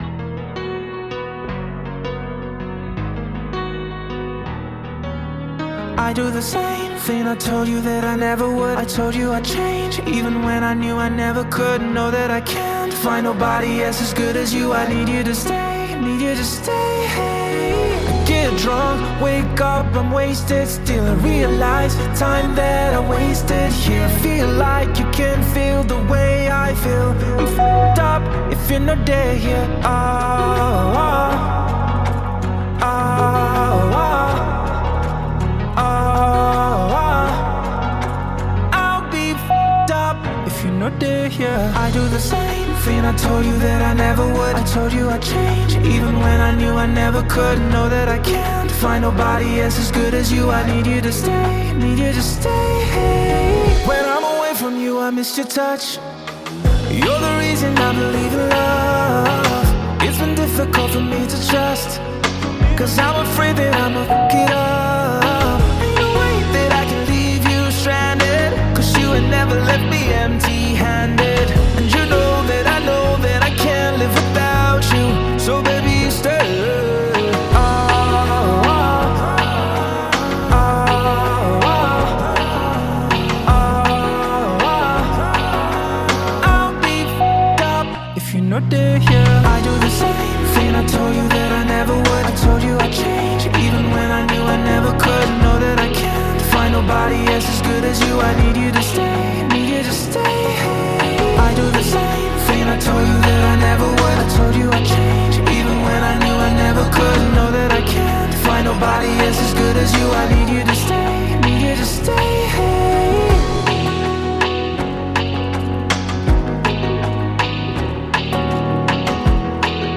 Popular Nigerian singer
drops a new meticulous and melodious new track
is equipped with nice melodious vibes